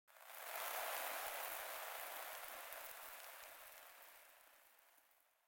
دانلود آهنگ بیابان 12 از افکت صوتی طبیعت و محیط
دانلود صدای بیابان 12 از ساعد نیوز با لینک مستقیم و کیفیت بالا
جلوه های صوتی